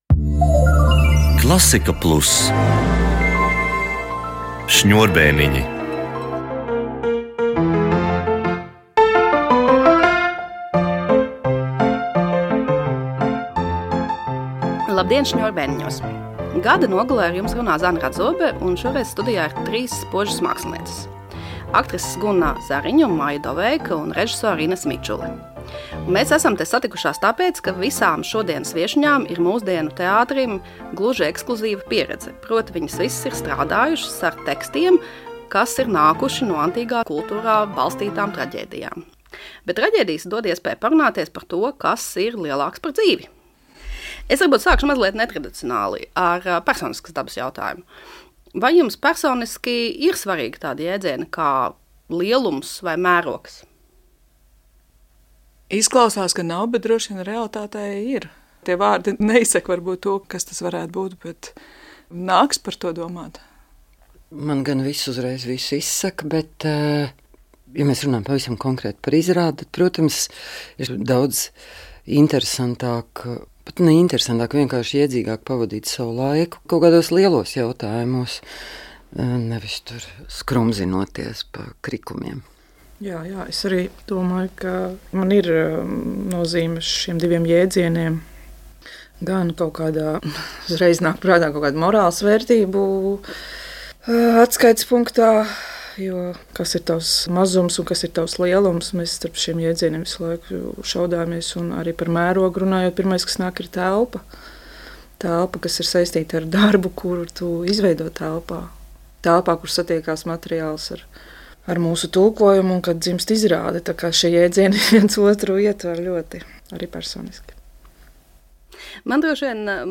sarunājas aktrises